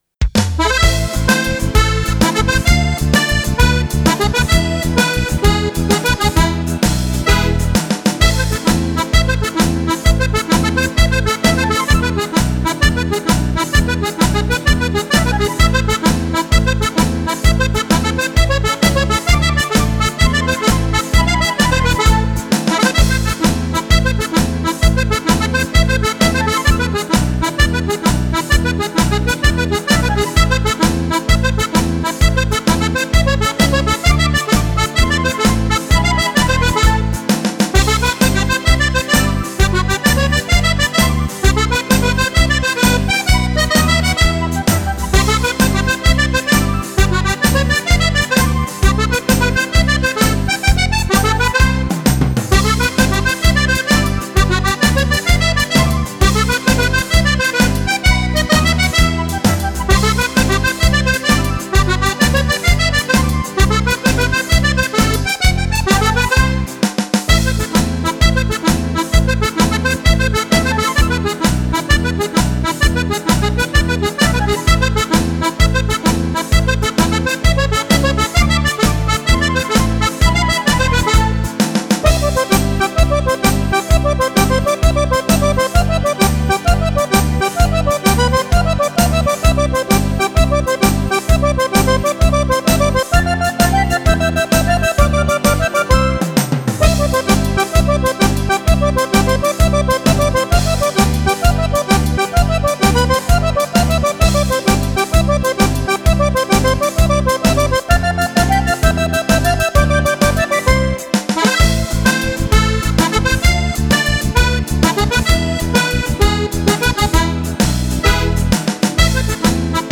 Tarantella
e 12 ballabili per Fisarmonica solista